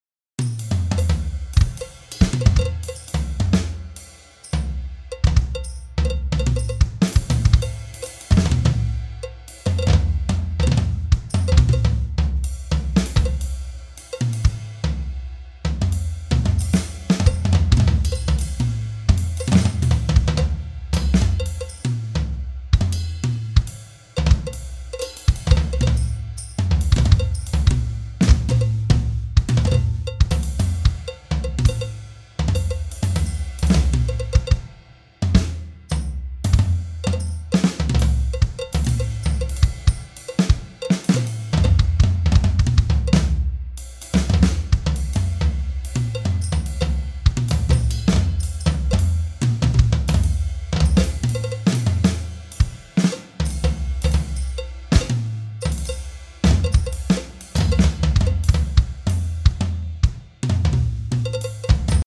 criticality_drums.mp3